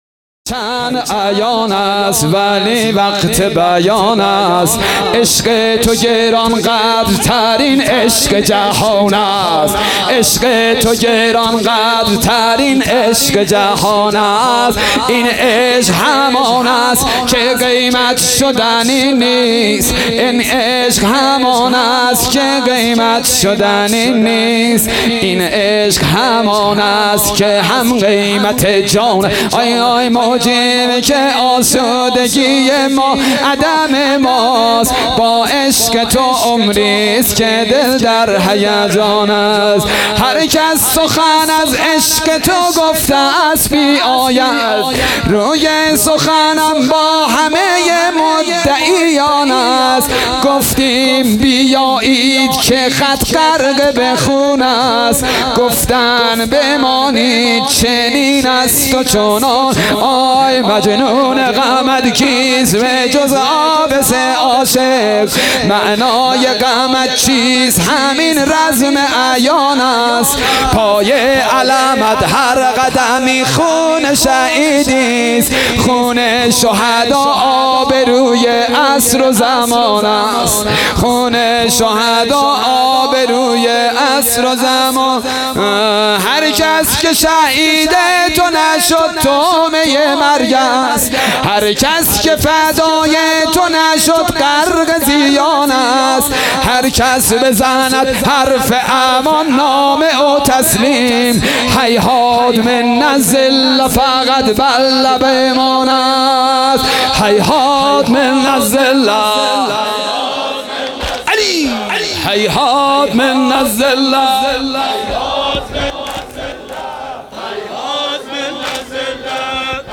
حسینیه بیت النبی - شب هفتم محرم الحرام 1443